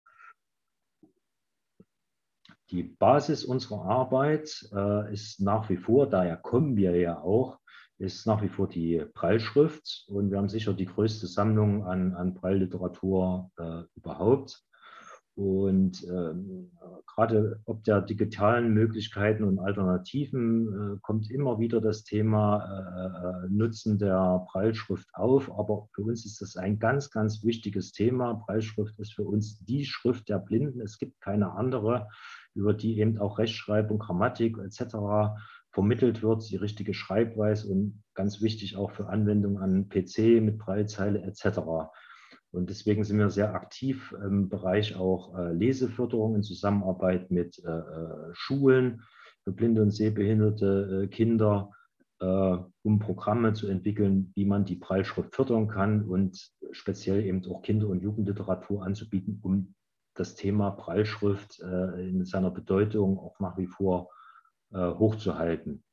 In unserem Beispiel des Themenabends über dzb lesen (Deutsches Zentrum für barrierefreies Lesen) habe ich nur eine sehr kurze Passage gewählt und ohne Zeitmarken transkribieren und abspeichern lassen.